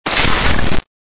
Помехи телевизора